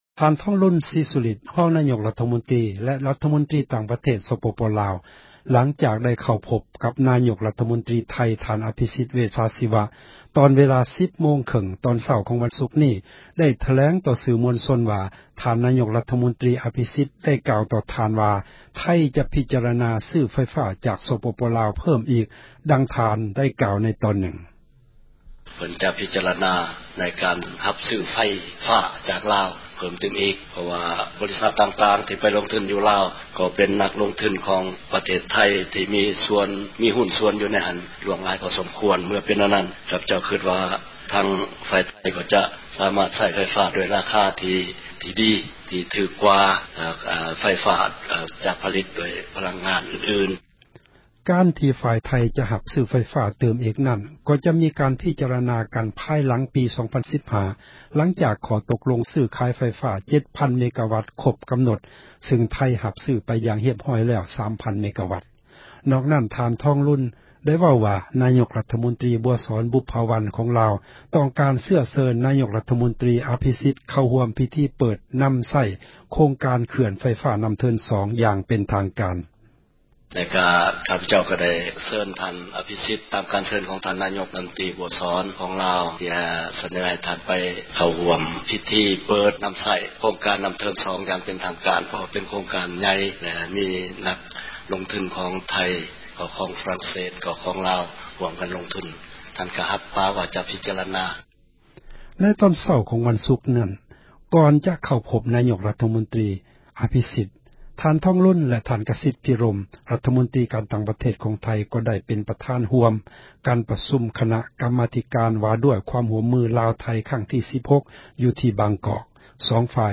ທ່ານ ທອງລຸນ ສີສຸລິດ ຮອງນາຍົກ ຣັຖມົນຕຣີ ແລະຣັຖມົນຕຣີ ຕ່າງປະເທດ ສປປລາວ ຫລັງຈາກໄດ້ພົບກັບ ນາຍົກຣັຖມົນຕຣີ ໄທ ທ່ານ ອາພິສິດ ເວດຊາຊີວະ ເວລາ 10:30 ຕອນເຊົ້າ ຂອງວັນສຸກນີ້ ໄດ້ຖແລງຕໍ່ ສື່ມວນຊົນວ່າ ທ່ານ ນາຍົກຣັຖມົນຕຣີ ອາພິສິດ ໄດ້ກ່າວຕໍ່ທ່ານວ່າ ໄທ ຈະພິຈາຣະນາ ຊື້ໄຟຟ້າຈາກ ສປປ ລາວ ເພີ້ມອີກ ດັ່ງທ່ານໄດ້ກ່າວ ໃນຕອນນື່ງ: